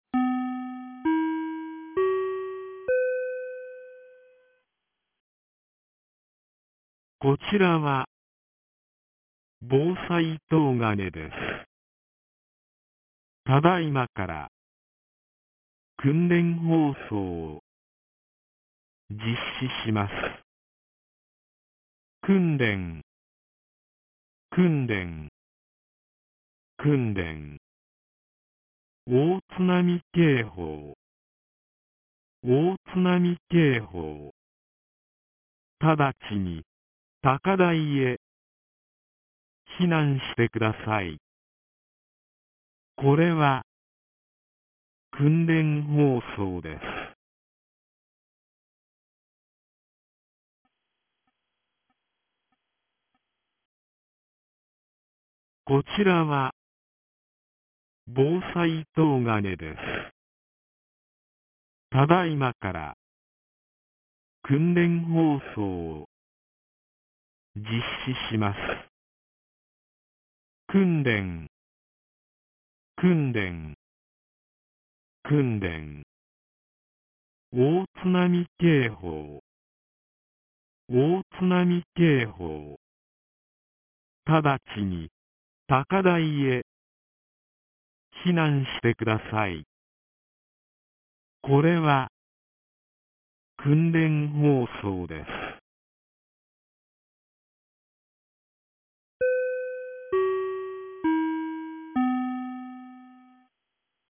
2025年11月01日 09時11分に、東金市より防災行政無線の放送を行いました。